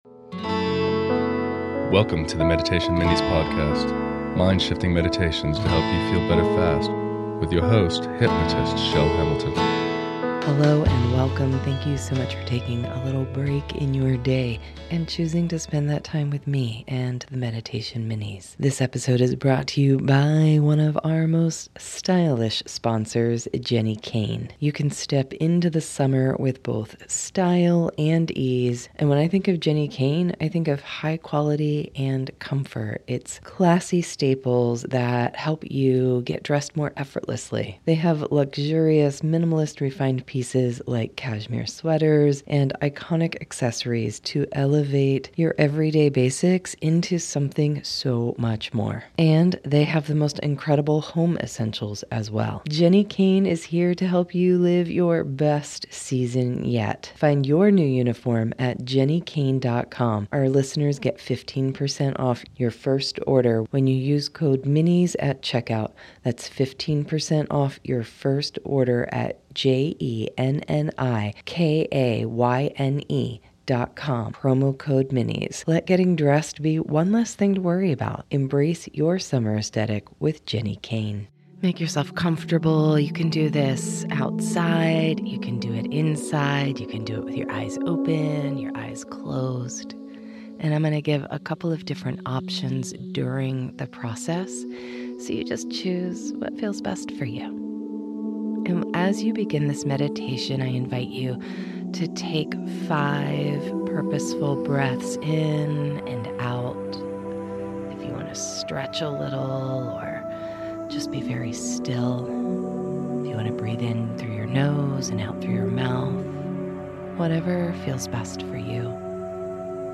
Let overwhelm, frustration, sadness, and worry drain away as you breath in that fresh cut grass feeling... and/or drift way in a hammock under the green leaves and soft blue summer sky with this guided meditation.